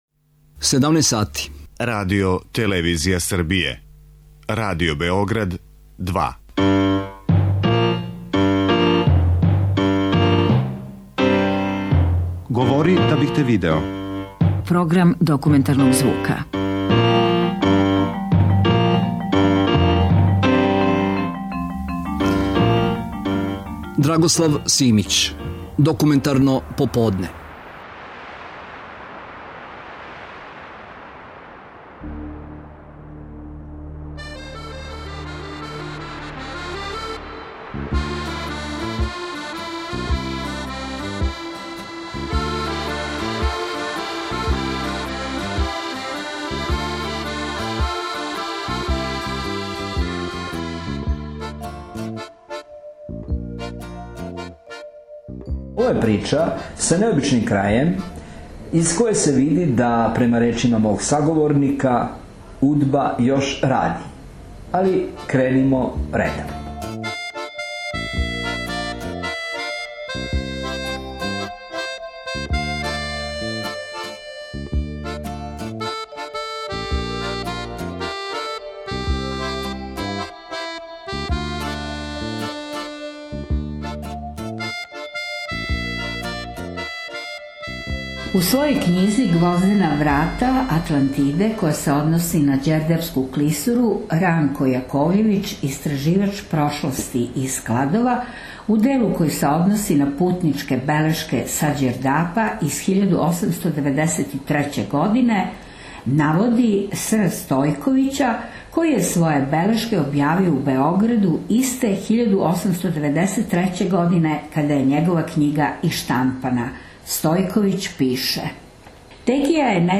Bio je to povod da odem u Tekiju, obližnje selo na Dunavu i tamo snimim ovu emisiju ''OPERACIJA RADIO'', priču o tome kako se vodio propagandni rat između Jugoslavije i Rumunije tokom Informbiroa pedesetih godina XX veka.